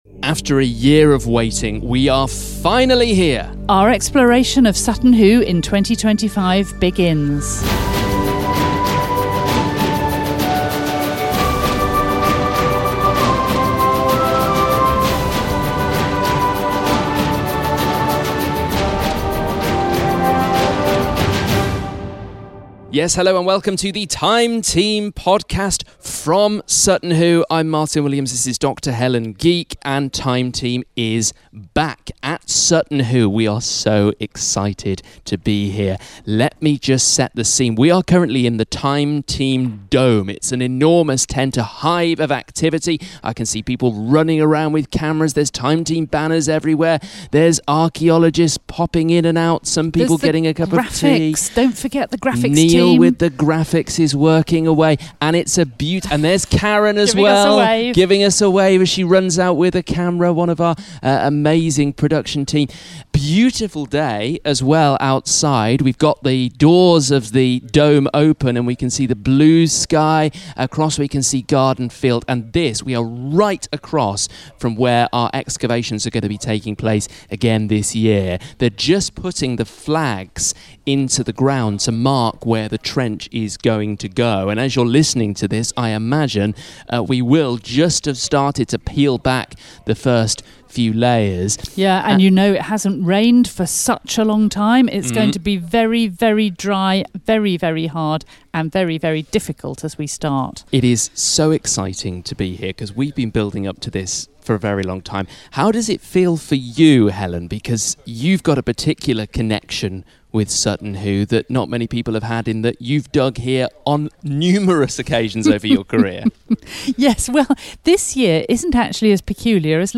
are at Sutton Hoo where Time Team's 2025 dig has just started.